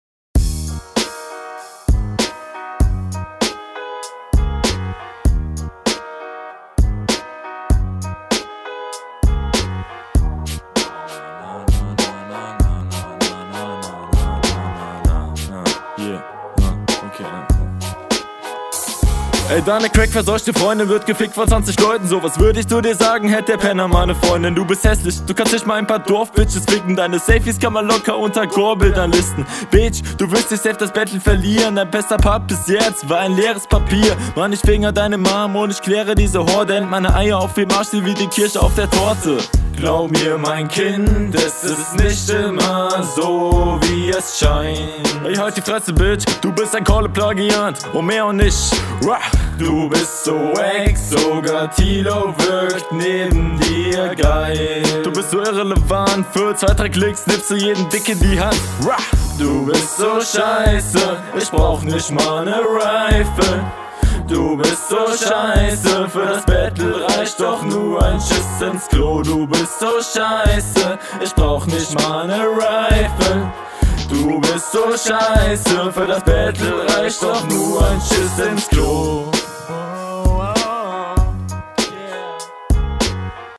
Soundquali is aber recht …